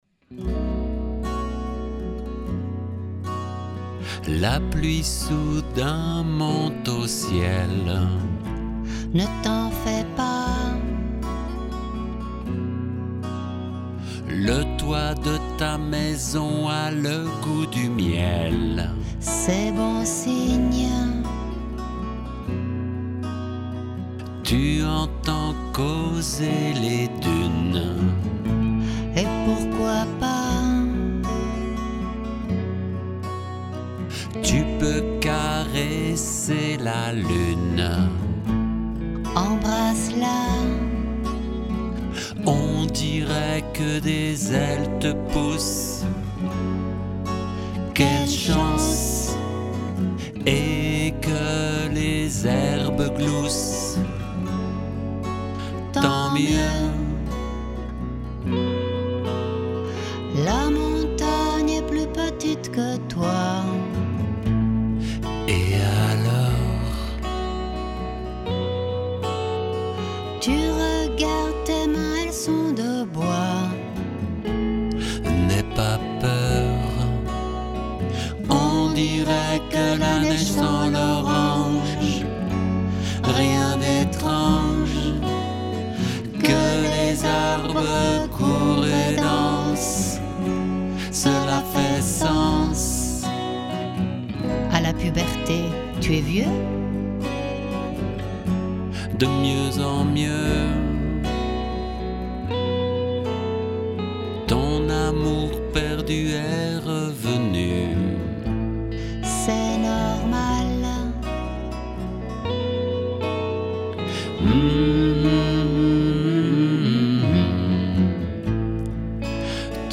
chanson en français